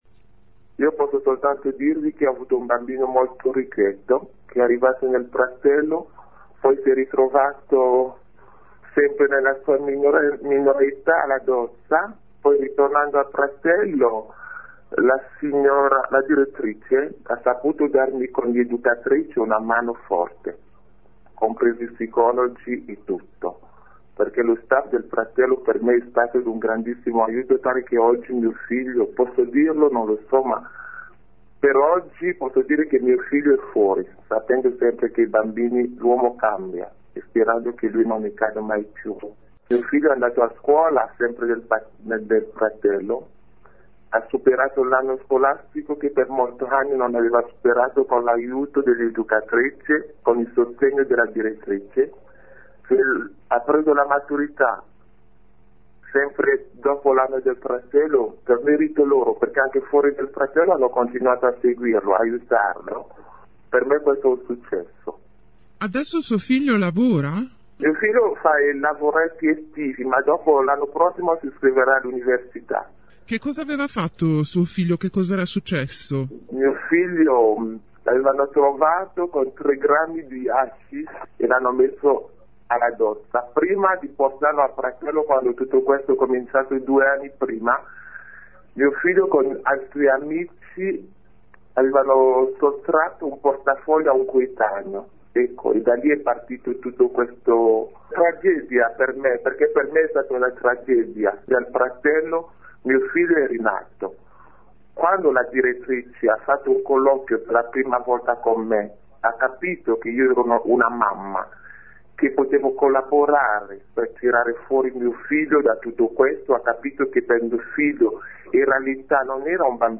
La mamma di uno di loro racconta ai nostri microfoni come suo figlio, grazie allo staff del carcere minorile, sia riuscito a finire la scuola e a fare la maturità.